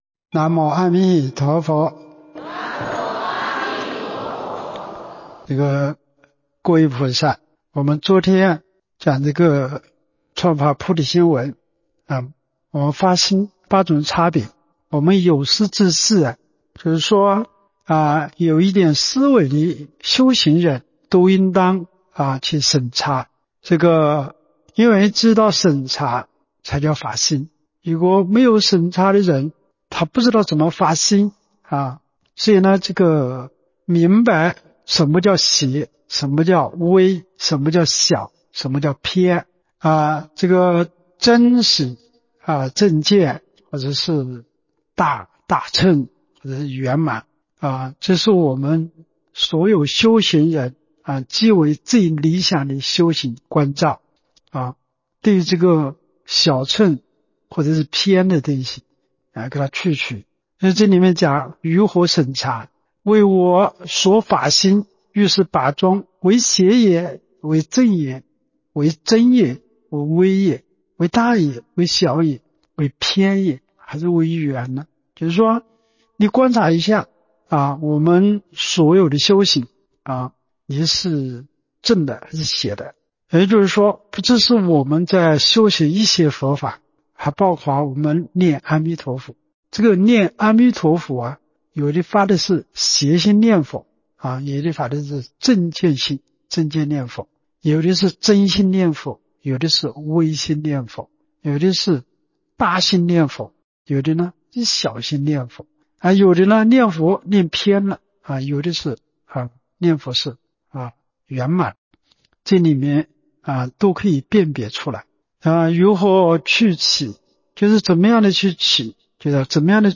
彭泽集福寺佛七开示